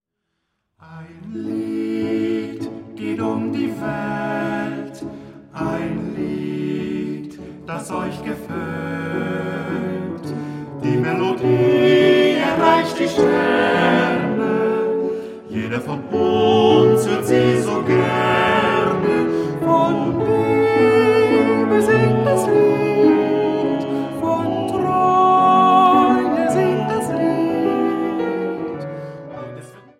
einen blauen Kristallglasflügel der Firma Schimmel